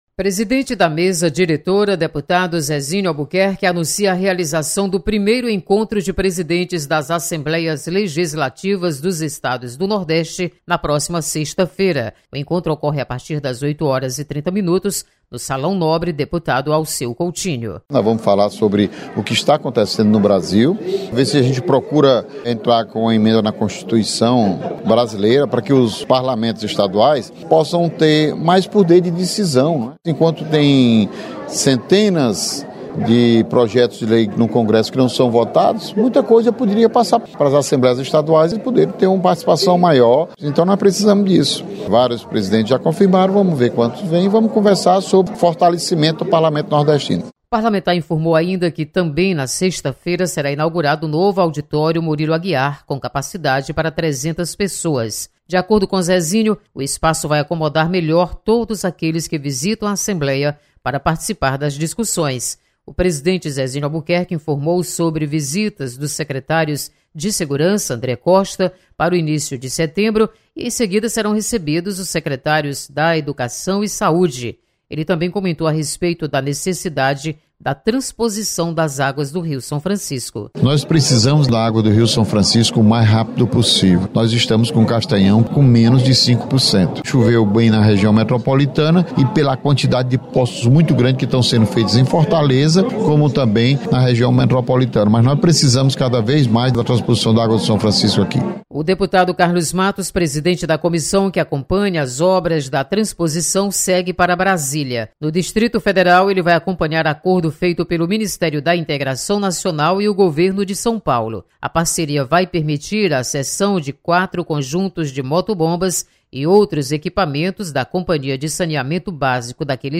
Presidente Zezinho Albuquerque anuncia encontro de presidentes dos parlamentos e inauguração do novo auditório Murilo Aguiar. Repórter